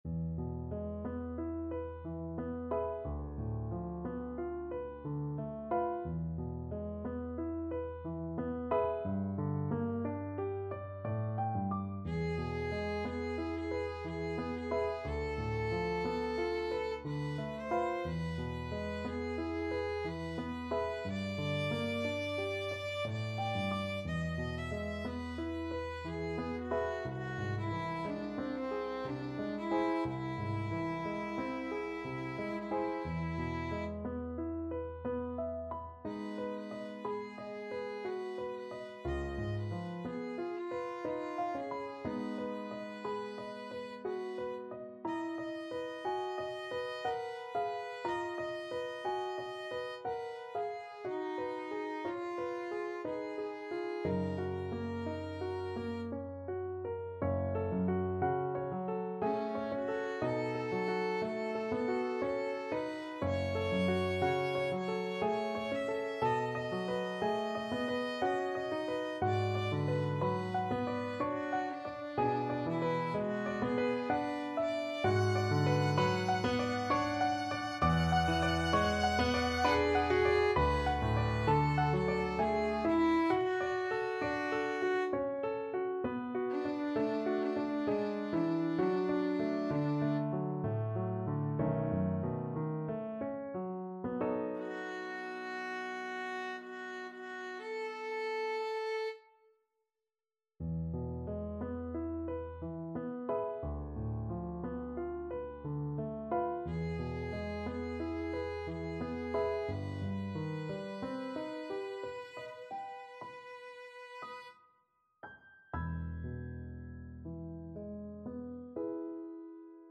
Free Sheet music for Violin
Violin
E major (Sounding Pitch) (View more E major Music for Violin )
Andante ma non troppo =60
C5-F#6
3/4 (View more 3/4 Music)
Classical (View more Classical Violin Music)
debussy_beau_soir_VLN.mp3